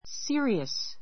serious 中 sí(ə)riəs スィ (ア)リア ス 形容詞 ❶ 真面目な , 真剣 しんけん な; （冗談 じょうだん でなく） 本気の a serious person a serious person 真面目な人 look serious look serious 真剣[深刻]な顔をしている ＊会話＊ Are you serious or joking?—I'm serious .